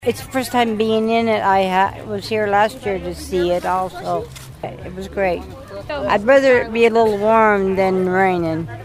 Senior citizen